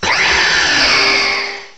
cry_not_lunala.aif